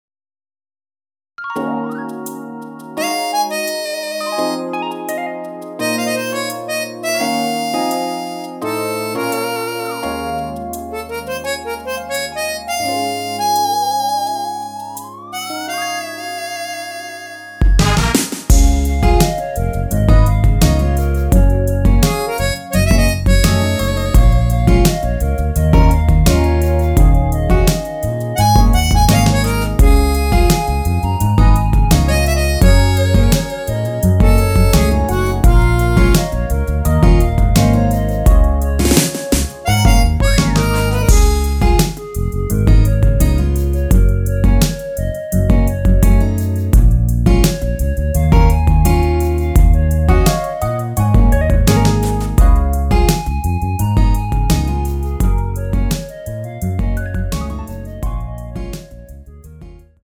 원키에서(-1)내린 멜로디 포함된 MR입니다.
Db
앞부분30초, 뒷부분30초씩 편집해서 올려 드리고 있습니다.
중간에 음이 끈어지고 다시 나오는 이유는